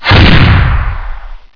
Reuse 0.9 and before's rocket launcher sound to make the revenant attack sound more distinct and impactful, allowing it to be heard easier than the quite weak "swoosh" it is currently. 2024-01-29 09:23:37 -08:00 17 KiB Raw Permalink History